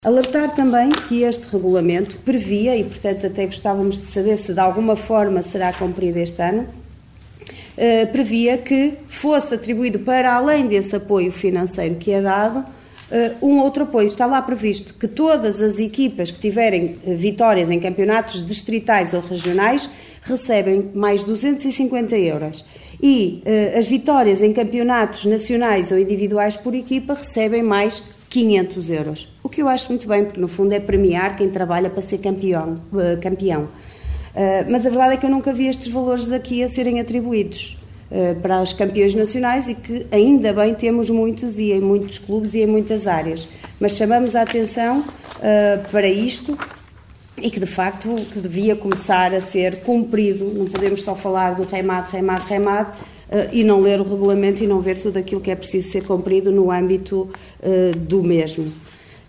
Este anúncio foi feito na última reunião do executivo em que foram aprovados por unanimidade vários contratos-programa com várias coletividades desportivas do concelho.